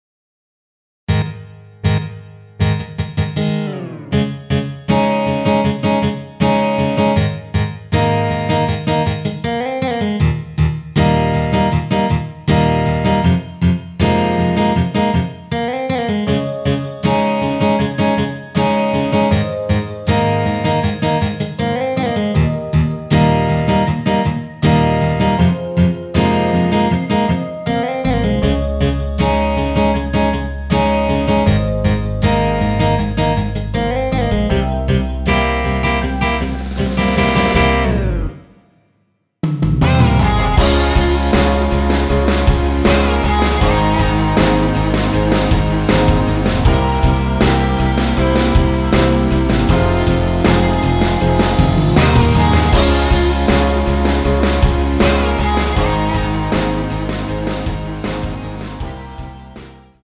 원키에서(-1)내린 MR입니다.
Fm
앞부분30초, 뒷부분30초씩 편집해서 올려 드리고 있습니다.
중간에 음이 끈어지고 다시 나오는 이유는